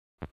next player silent klack Meme Sound Effect
next player silent klack.mp3